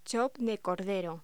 Locución: Chop de cordero
voz